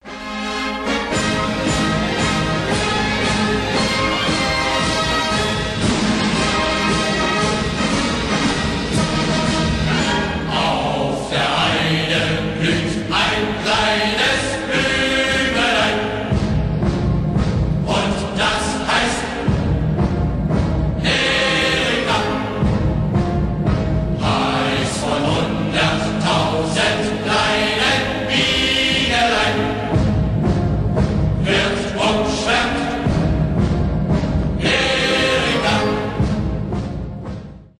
ретро музыка.
марш